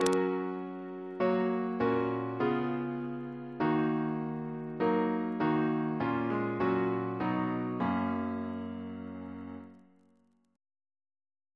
Single chant in G♭